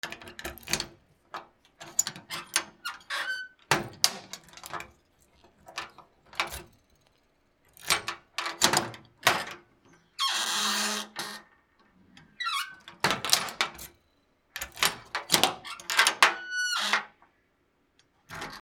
/ M｜他分類 / L05 ｜家具・収納・設備